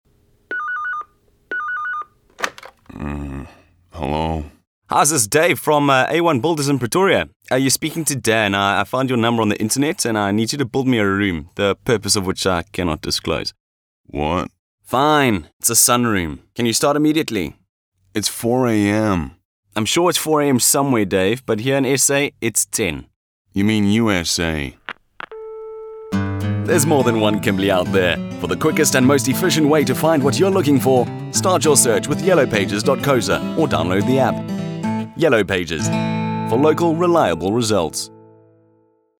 South Africa
agile, brisk, fast-paced, rapid
My demo reels